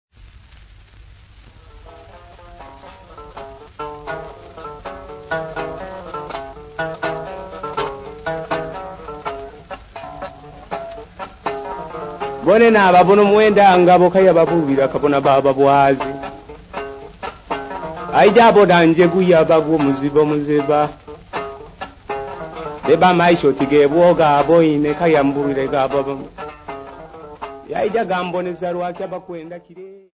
Popular music--Africa
Dance music
Field recordings
Africa Tanzania Bukoba f-sa
A recitative Legend accompanied by the Nanga trough zither with 7 notes